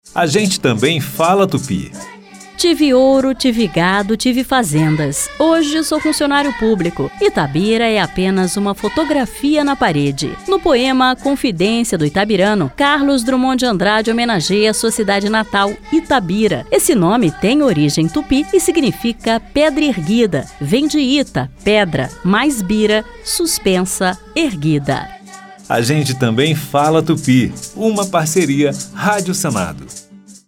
A Rádio Senado preparou o sexto grupo de dez spots da série “A gente também fala tupi”.